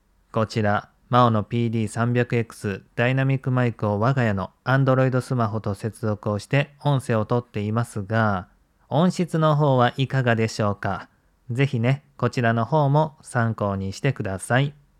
MAONO PD300XT レビュー！スマホとUSB接続の音声：マイクとの距離13cm
私個人的には、どれも納得できる温かみある音質に驚きを隠せないでいる現状です。